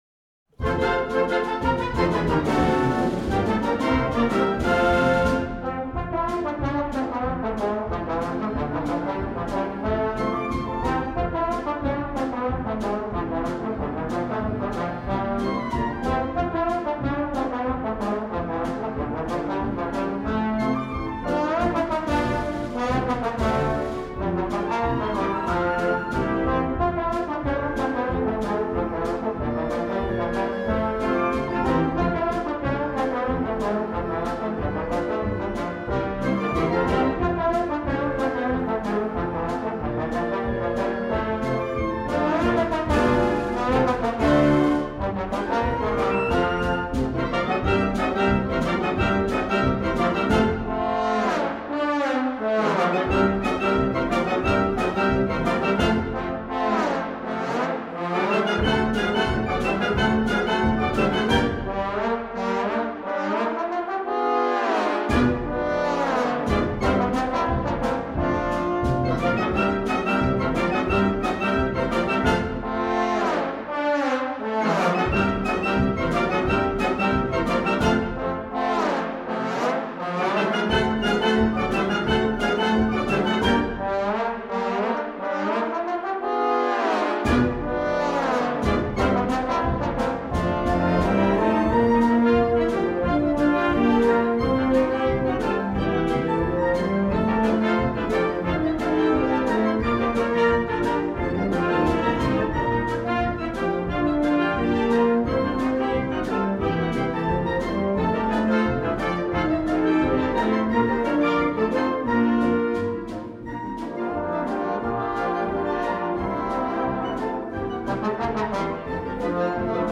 Voicing: Trombone Feature w/ Band